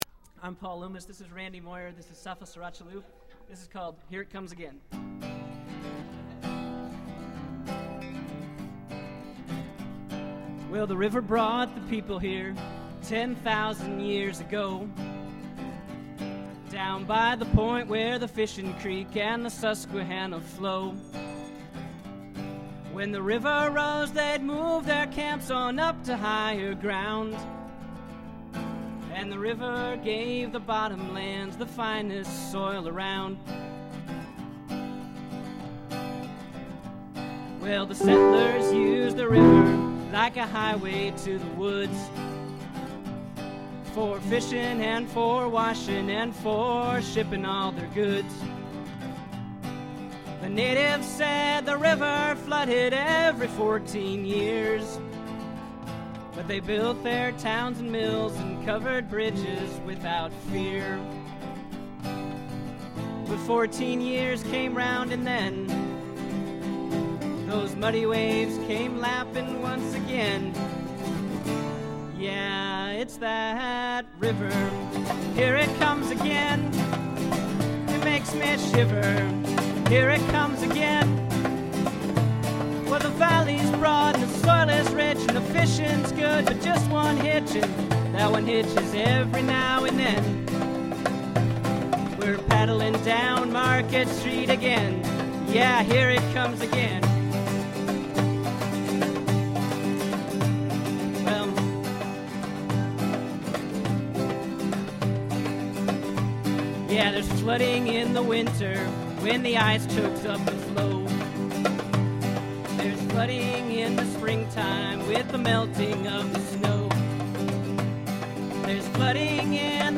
Harmonica Solo